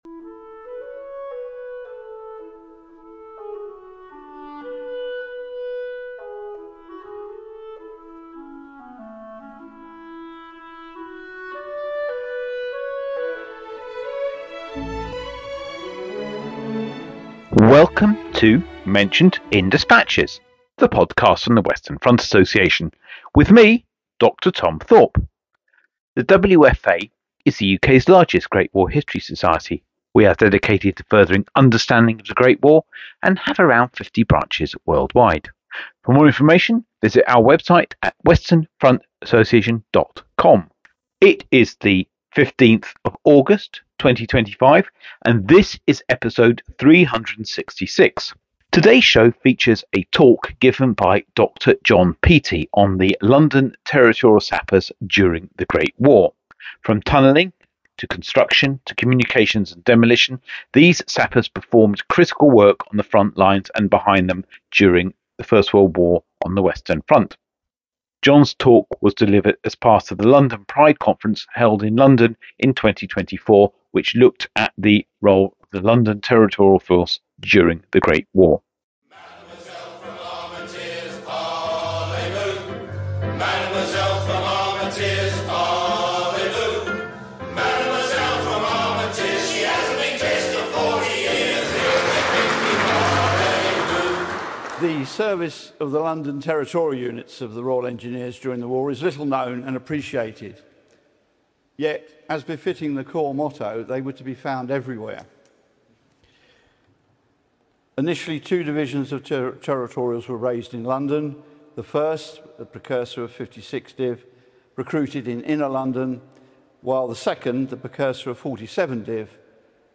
This talk was part of the London Pride Conference held in June 2024.